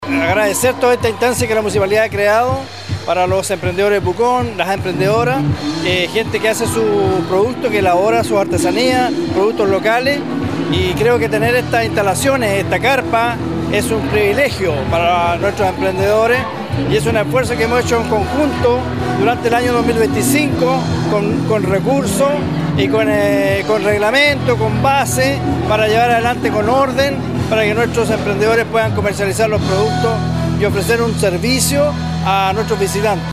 Julio-Inzunza-concejal-de-Pucon-destaca-este-espacio-para-promover-el-turismo-.mp3